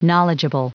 Prononciation du mot knowledgeable en anglais (fichier audio)
Prononciation du mot : knowledgeable